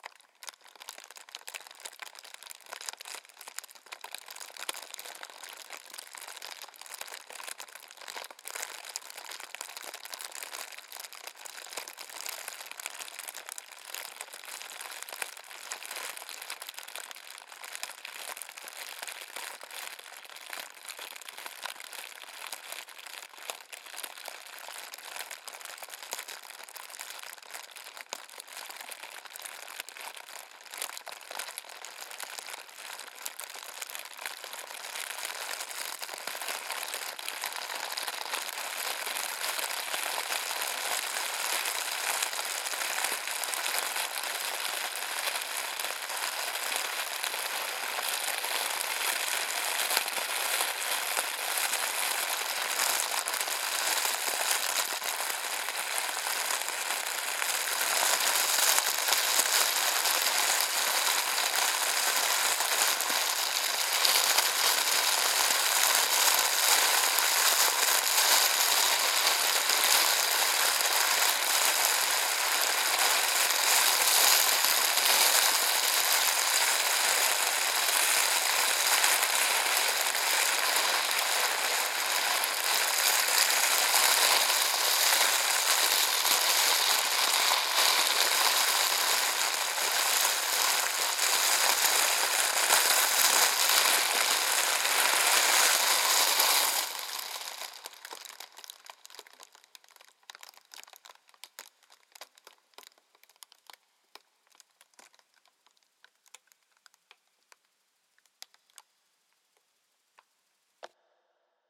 Some noisy cellophane plastic that I thought I'd use for some recording. It makes a good crackling fire sound, or hail or rain, or a very ‘STICKY’ sound! The “intensity” of the sound varies through the recording so you can find a starting or stopping section (right click to save)
Keywords: burn, burning, cellophane, crackling, crinkly, fire, hailing, plastic, raining, sweet, weather, wrapper, wrapping, downpour,storm. filename:crackling_rain_fire.mp3 size 2740kb.
crackling_rain_fire.mp3